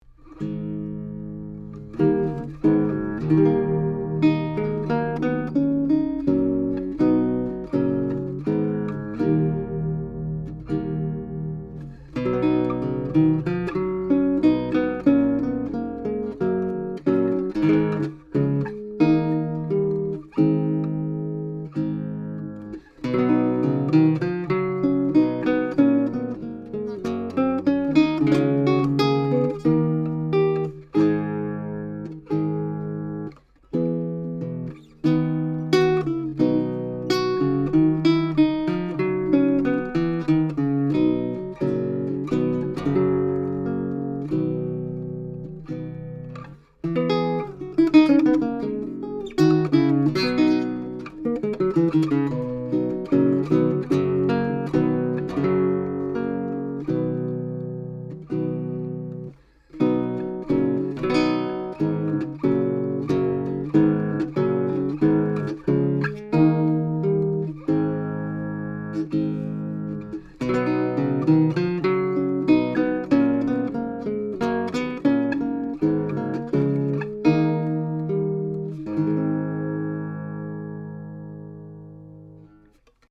Romantic Guitar
This guitar is sweet sounding, and has beautifully balanced bass and treble registers.
Here are a few quick sound files to give you an idea of the sound quality of guitar's voice. I used a pair of Roswell Pro Audio Mini K47 mics into a Trident 88 recording console, using Metric Halo ULN-8 converters:
K47sT88Pavan.mp3